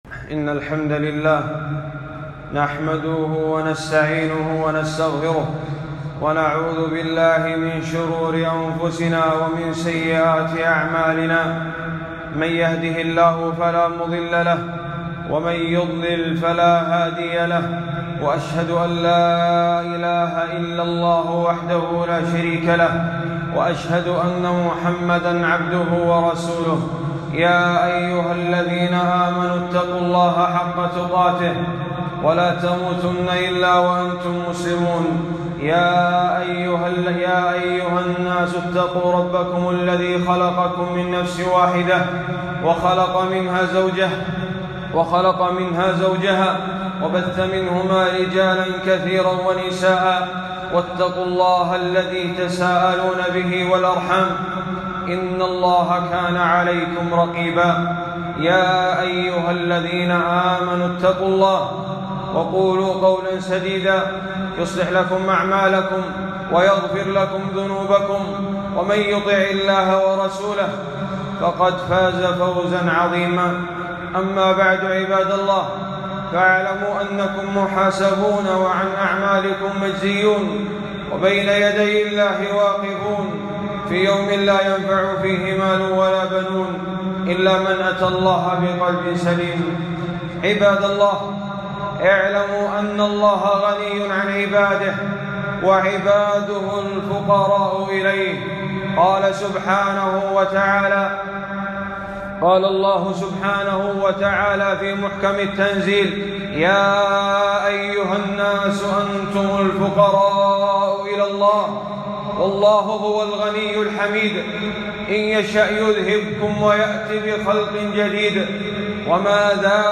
خطبة - وتوبوا إلى الله جميعًا